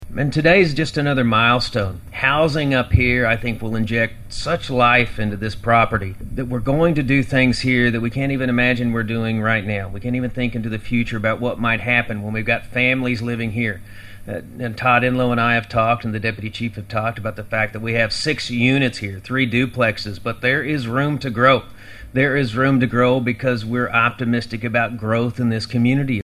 The Cherokee Nation celebrated the grand opening of new housing units and the remodel of the Washington County Cherokee Association Community Building in Ochelata on Tuesday.